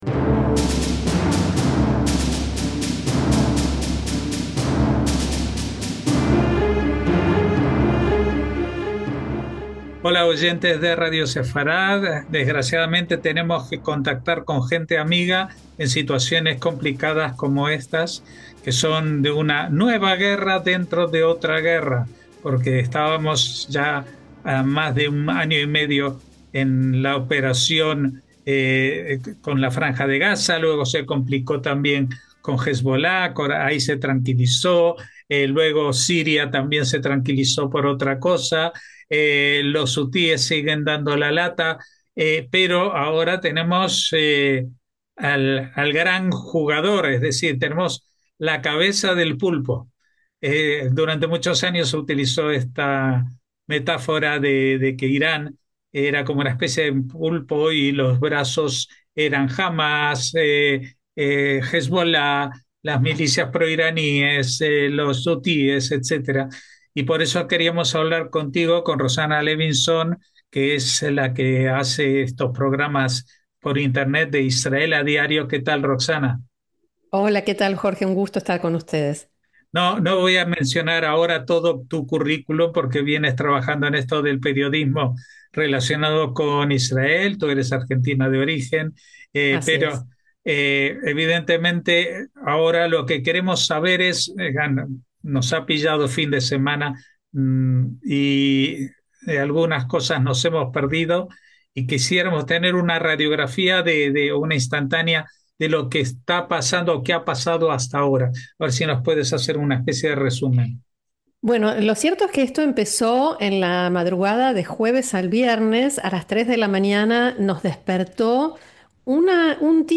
Uno puede quedar deslumbrado por la eficiencia de la aviación y los servicios de inteligencia israelíes que han sembrado el desconcierto entre la cúpula militar iraní, o sentirse conmovido por la solidaridad de los civiles israelíes agredidos. Vale la pena escuchar lo que la periodista argentino-israelí tiene para contarnos desde su refugio.